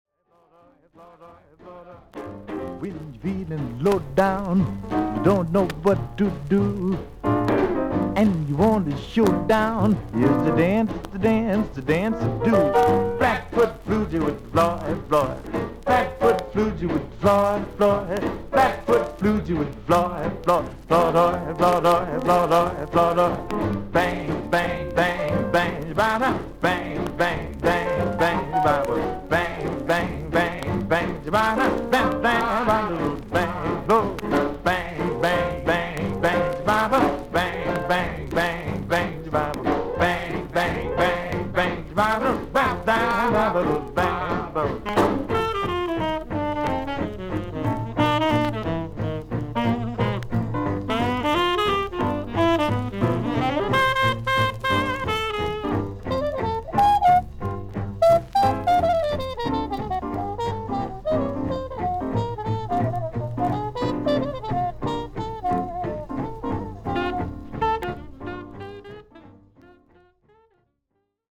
A1中盤に5mmほどのキズ、少々軽い周回ノイズあり。
プレスが悪いせいか少々さわさわとサーフィス・ノイズあり。
ジャイブ/ジャズ/エンターテイナー。